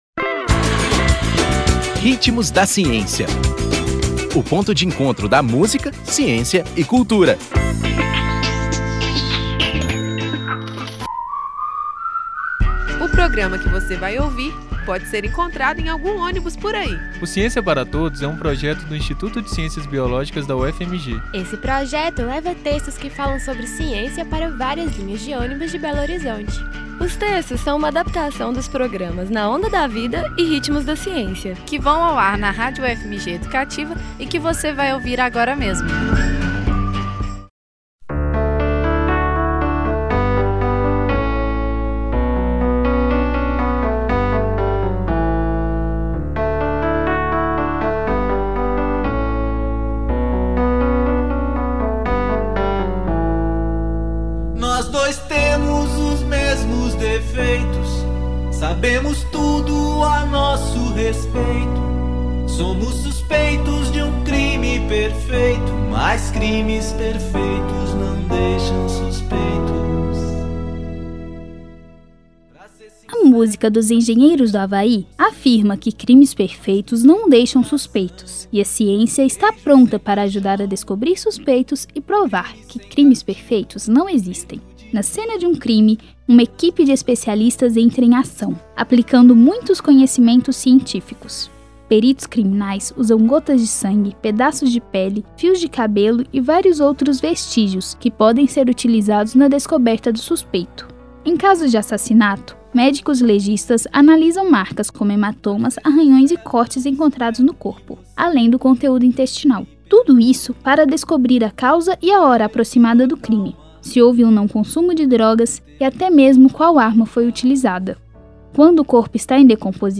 Nome da música: Pra Ser Sincero
Intérprete: Engenheiros do Hawaii